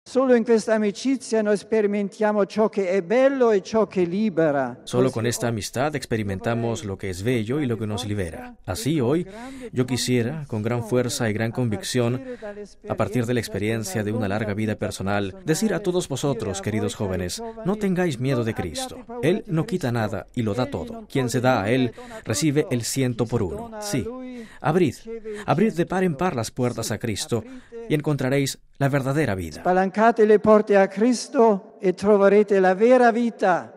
Palabras de Benedicto XVI en la primera homilía de su Pontificado.